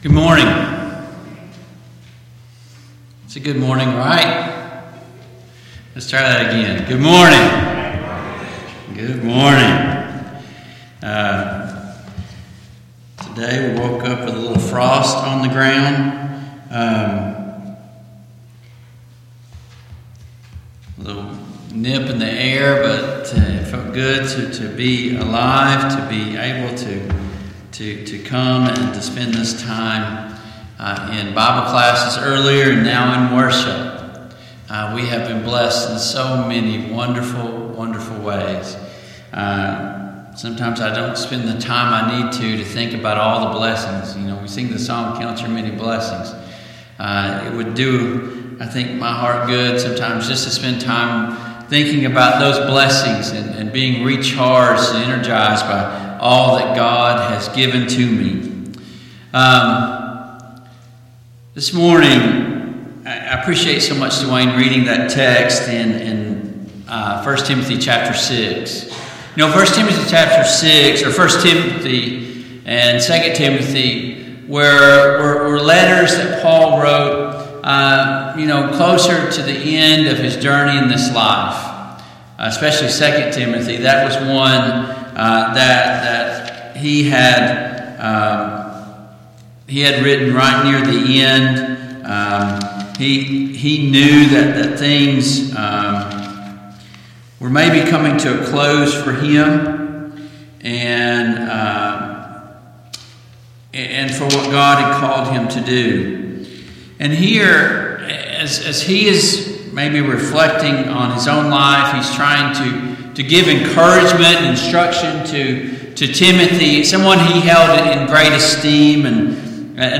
Service Type: AM Worship Topics: Overcoming Satan , Overcoming Sin & Temptation